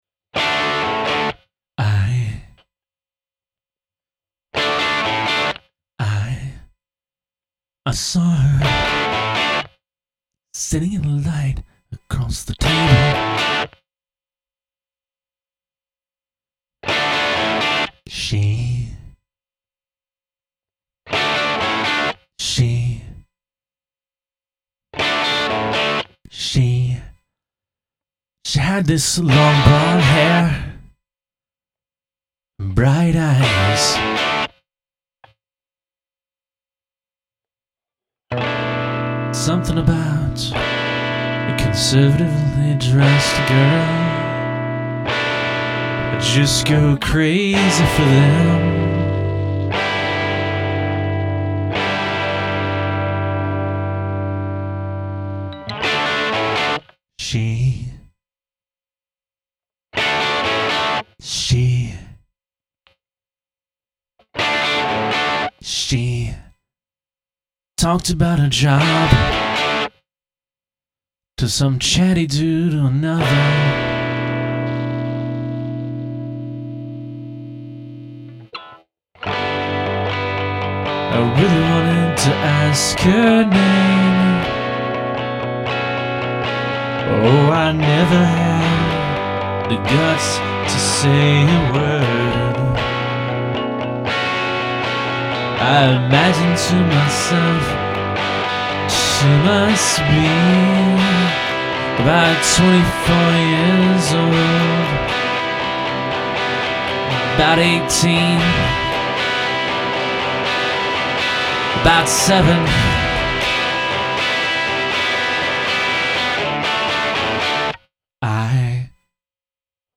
The negative space really makes the song interesting. I like how when the guitar and vocals are seperate, they still overlap a little bit. Also the rhythm is a little funky, which is nice.